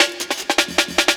61LOOP03SD.wav